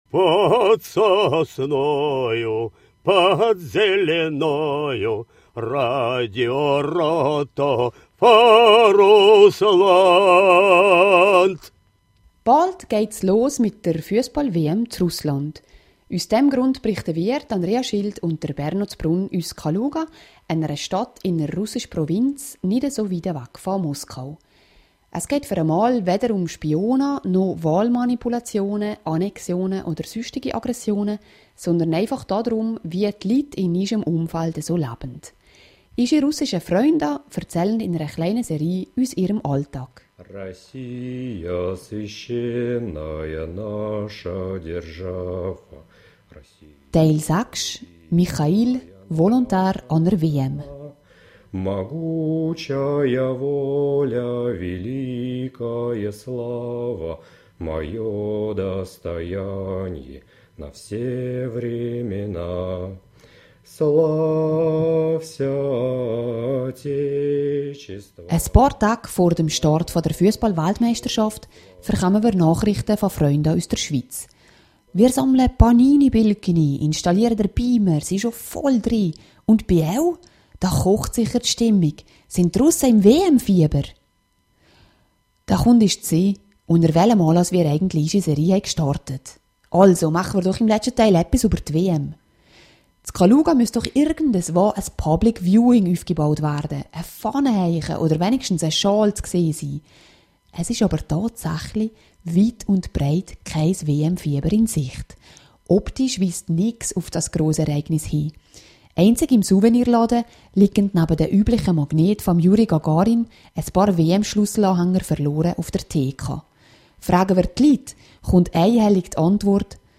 aus der russischen Stadt Kaluga